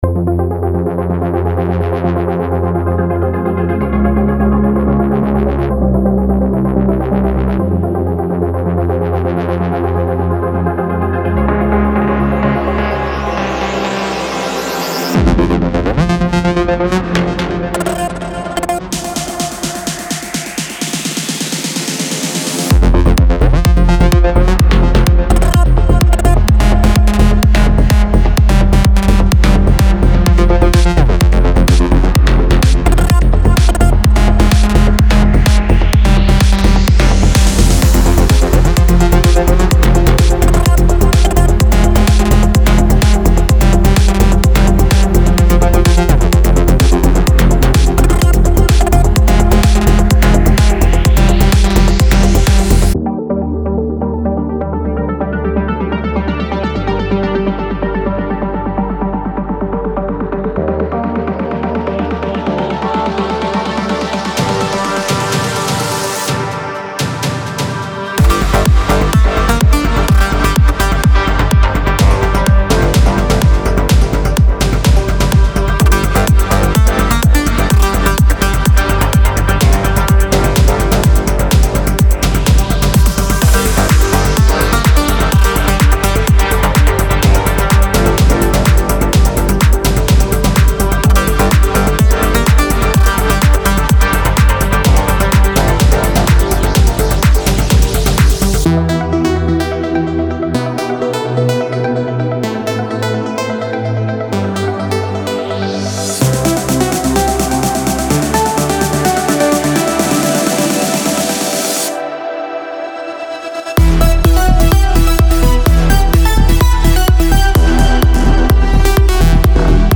House Melodic Techno
Construction Kits.
(Kits BPM 126 – 128).